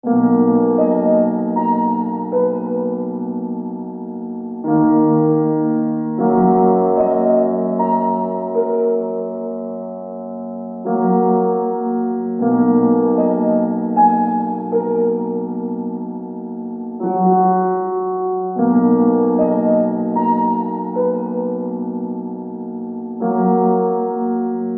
钢琴汁Wrld类型循环 命运
标签： 155 bpm Trap Loops Piano Loops 4.17 MB wav Key : Gm
声道立体声